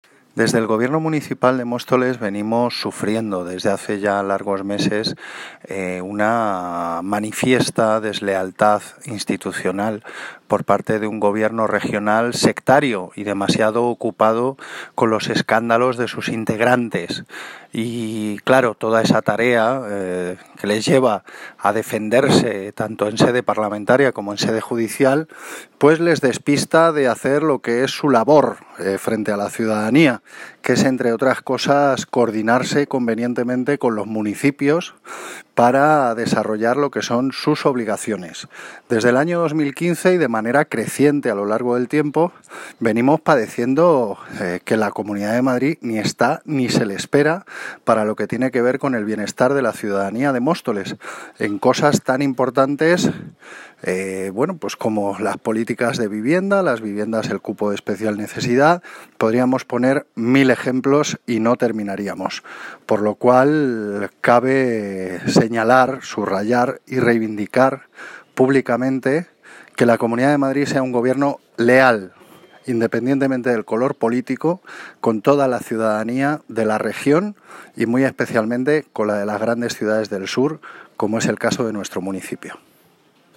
Audio - Gabriel Ortega (Concejal de Cultura, Bienestar Social y Vivienda) Sobre Deslealtad Política